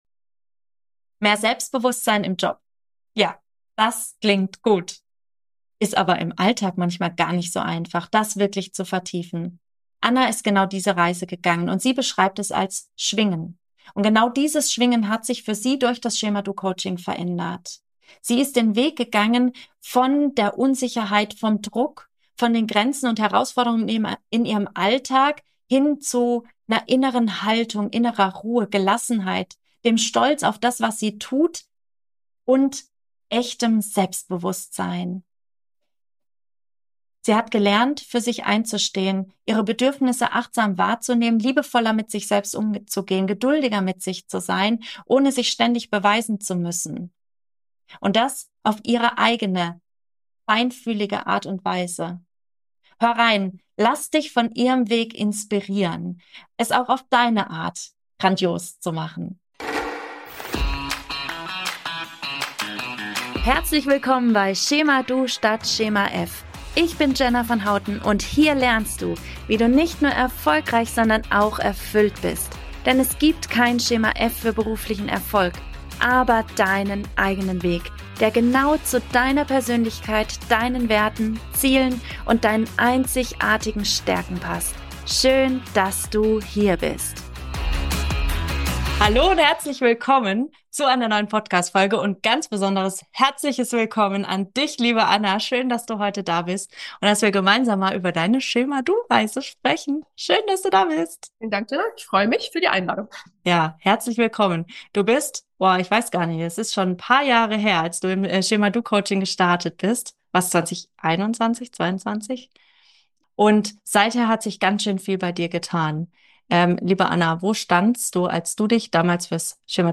Ich wünsche dir ganz viel Freude und viele wertvolle Impulse mit diesem Interview.